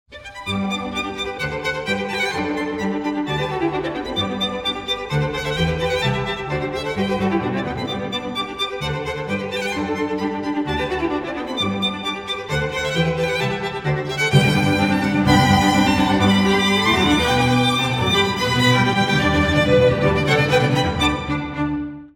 • Качество: 192, Stereo
красивые
инструментальные